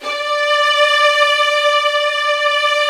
素材は倍音を多く含みますバイオリンです。
Tools/Crossfade Loopを使い、美しくLoopを整えます。